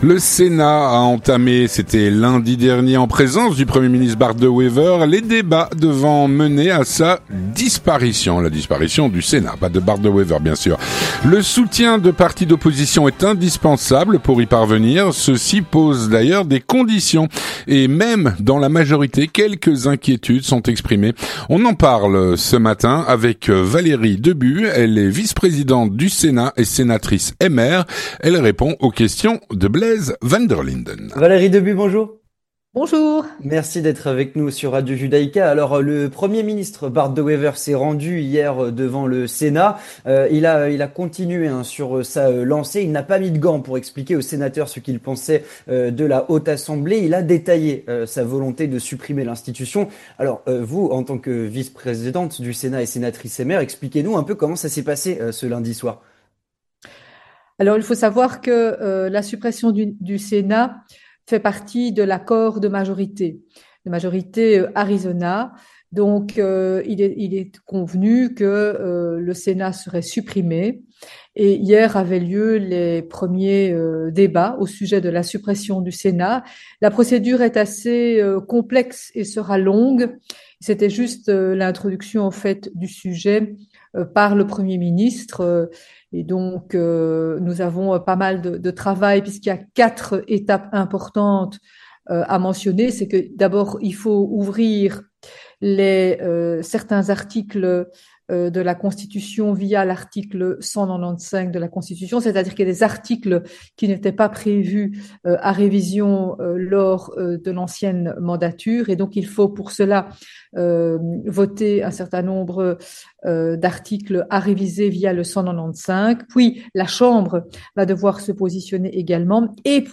On en parle avec Valérie De Bue, vice-présidente du Sénat et sénatrice MR.